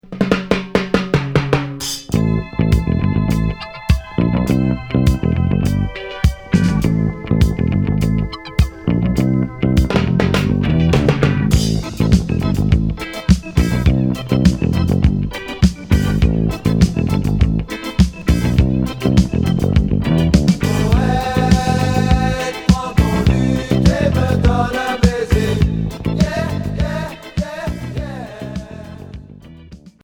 Reggae rock Unique 45t retour à l'accueil